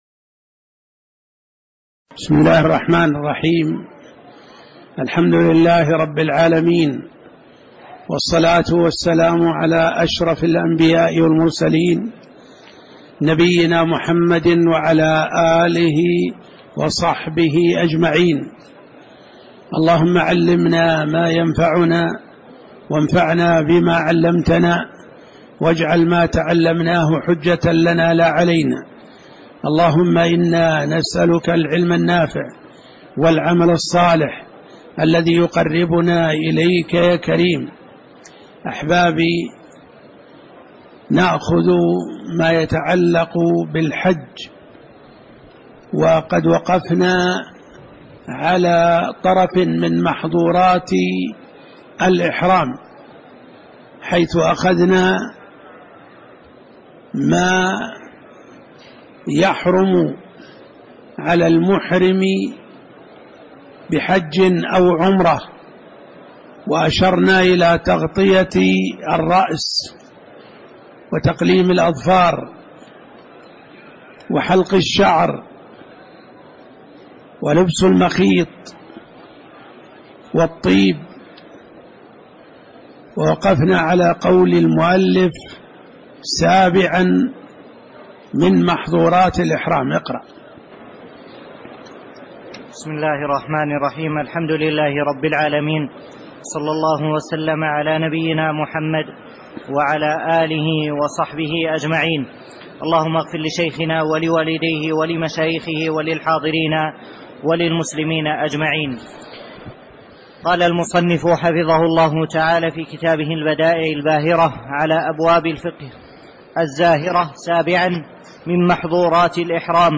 تاريخ النشر ١٨ ذو القعدة ١٤٣٨ هـ المكان: المسجد النبوي الشيخ